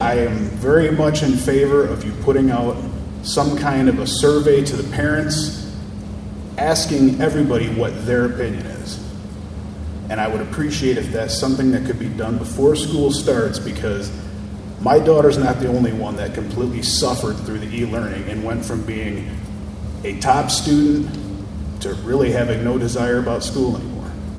An audience at the Ottawa High School Board meeting tonight asked that there be no mask requirement.